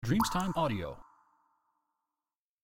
Wasser-Tropfen 01